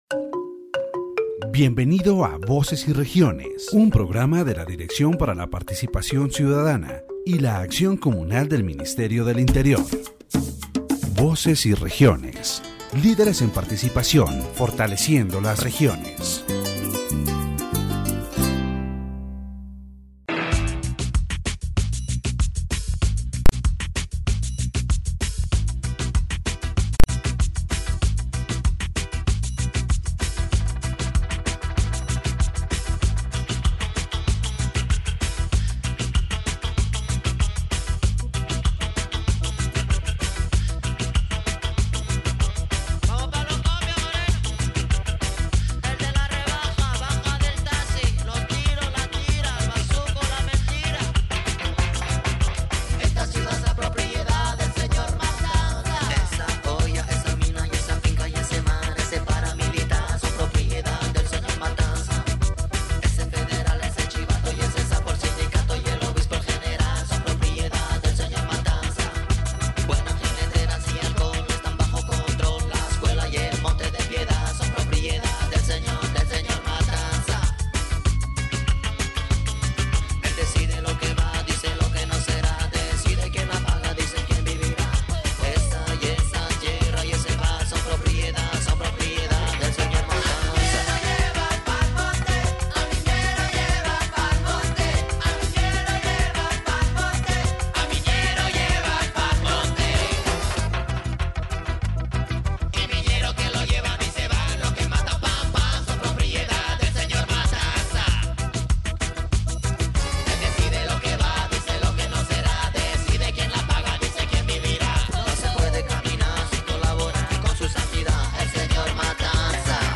In this section of the Voces y Regiones program, a farmer who was kidnapped by the guerrilla shares his testimony about the forced recruitment of young people, who were coerced or deceived into joining these armed groups. He recounts how, due to the pressure of violence in his territory, he was forced to flee, leaving behind his home and way of life in the countryside.